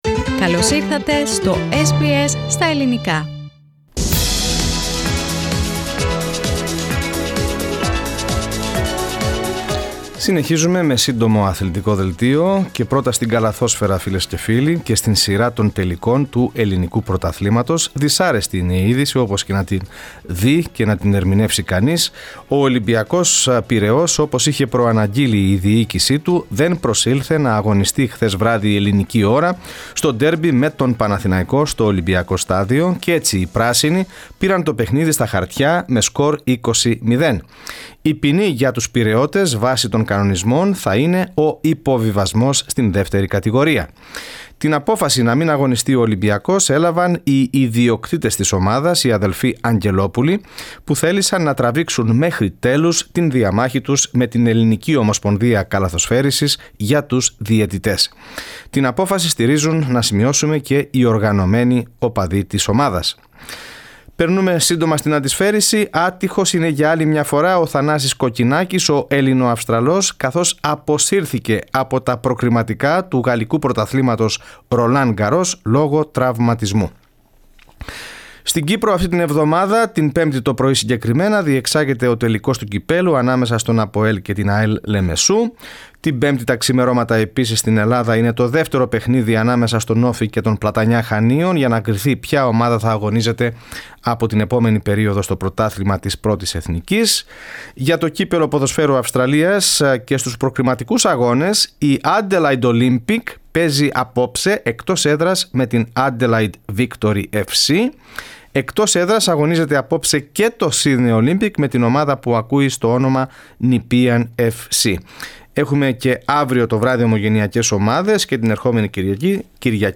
The Sports Bulletin of the day (21.05.2019)